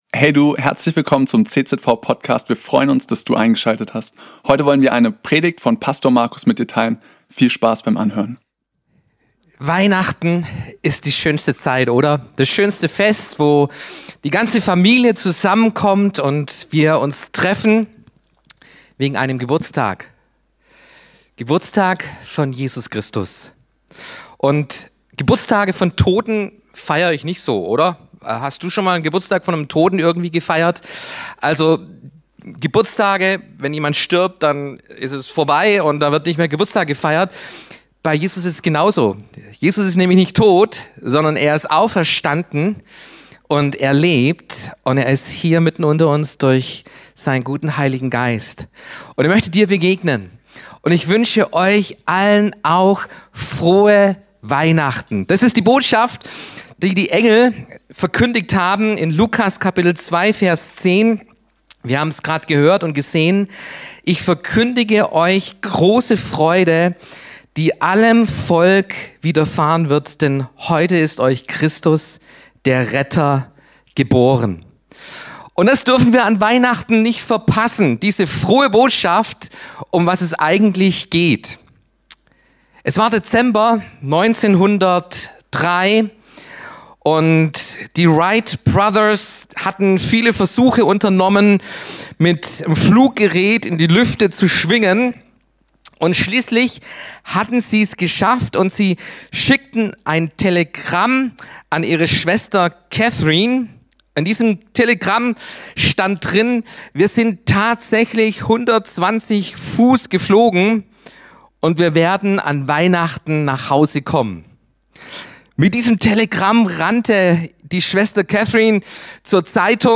Predigt
an Heiligabend 2025.